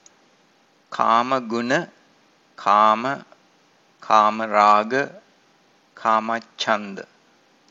• Aussprache der Begriffe: